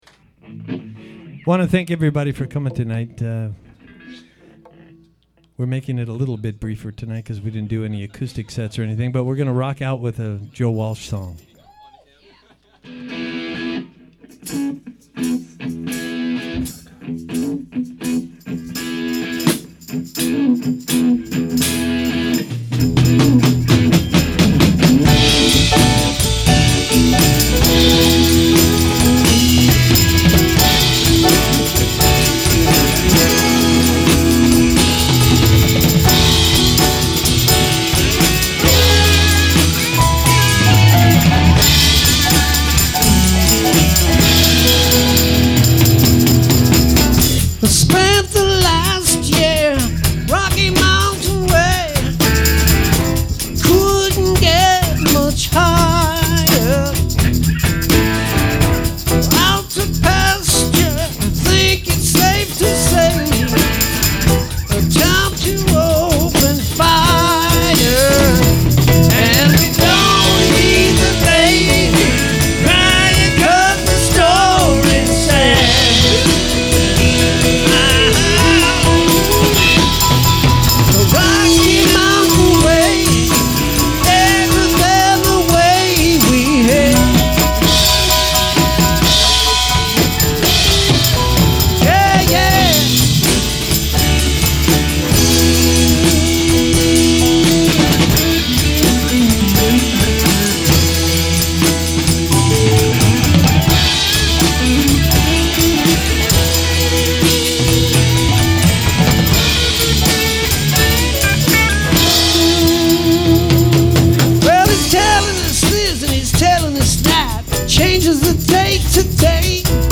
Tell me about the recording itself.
February 20th, 2010 Unfortunately, there was a malfunction in the recorder at the show Feb 20, 2010... Also, perhaps not a wise choice was made to record directly from the mixing board...